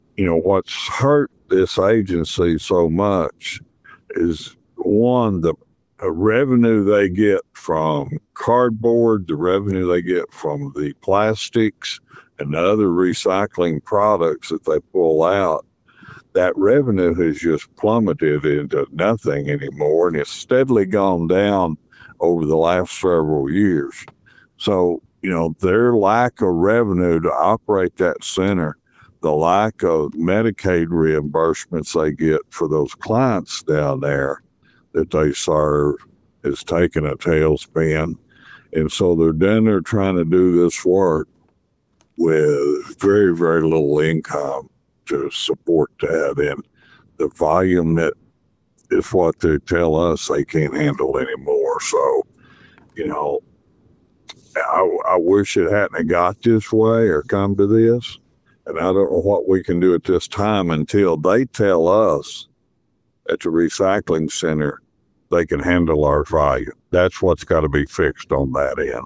The Mountain Home Mayor says the center has been hit with several hardships in recent years.